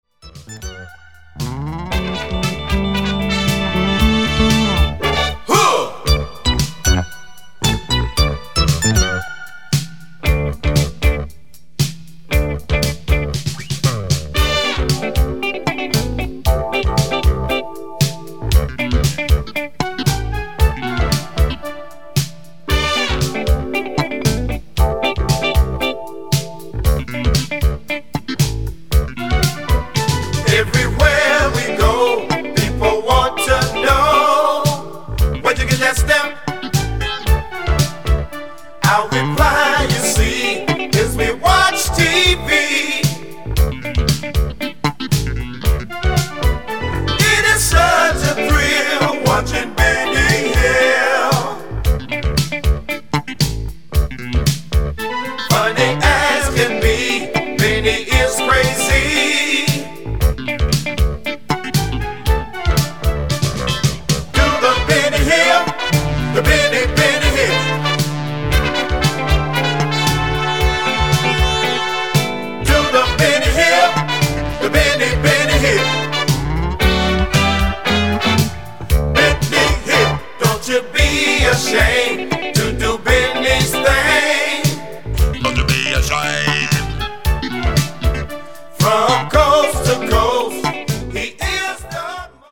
a funk band
electric funk to P-funk-like